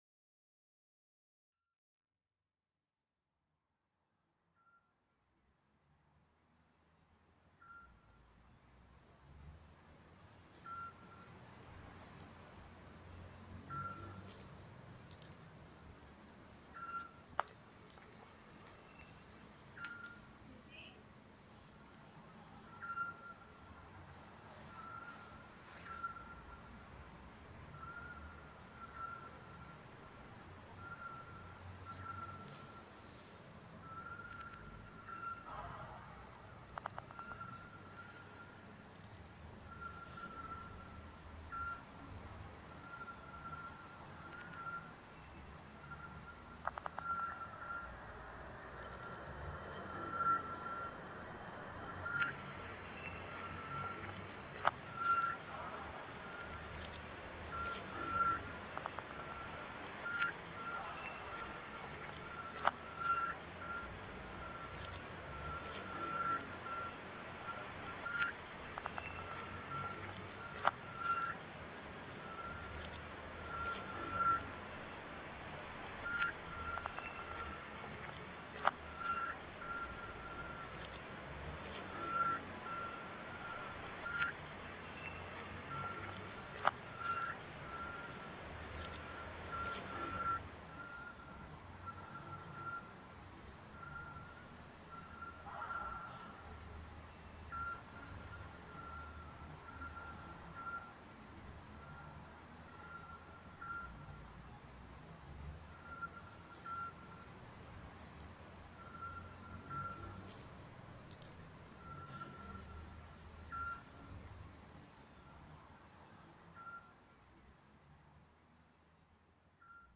Thessaloniki lockdown sound reimagined